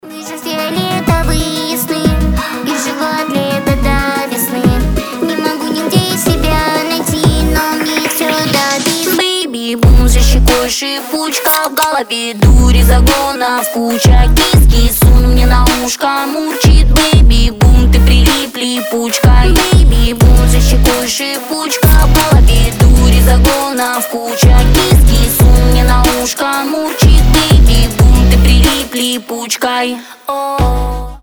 • Качество: 320, Stereo
teen pop